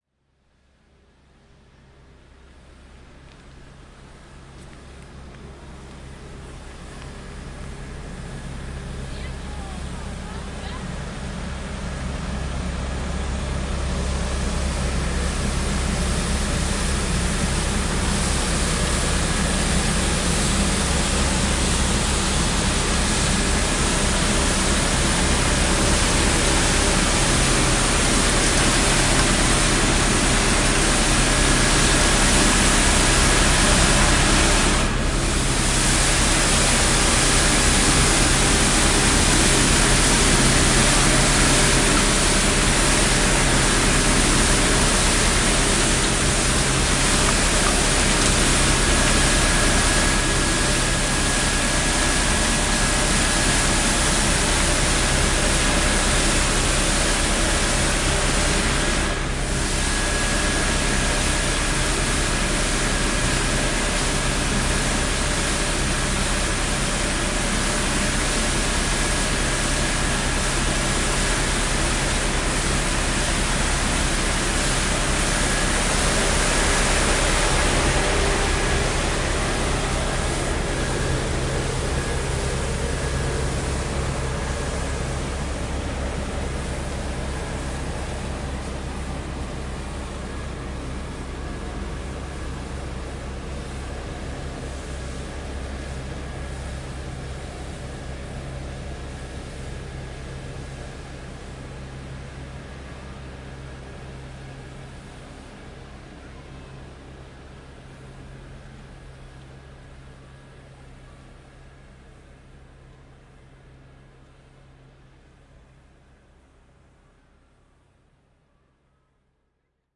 蒙特利尔 " 鼓圈 Tamtams3 蒙特利尔，加拿大
描述：鼓圈Tamtams3蒙特利尔，Canada.flac
声道立体声